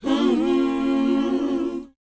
WHOA G#D.wav